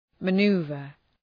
Shkrimi fonetik {mə’nu:vər}